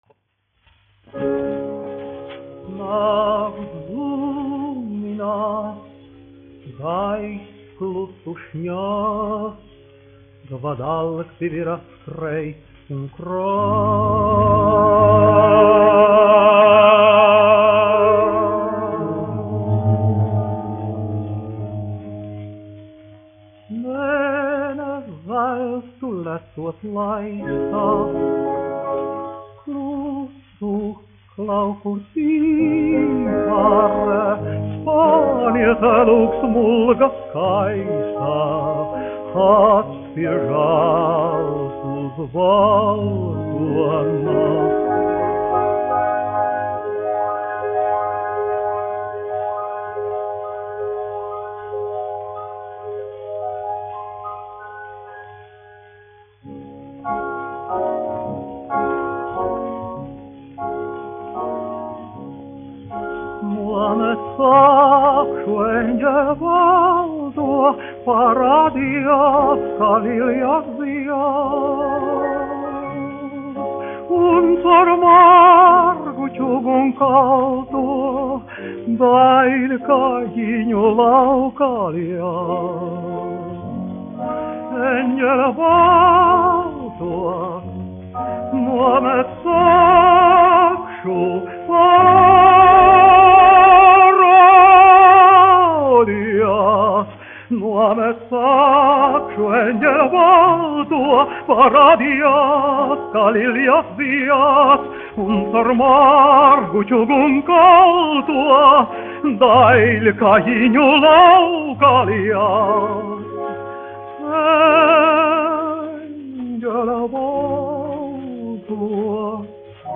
1 skpl. : analogs, 78 apgr/min, mono ; 25 cm
Dziesmas (augsta balss) ar klavierēm
Latvijas vēsturiskie šellaka skaņuplašu ieraksti (Kolekcija)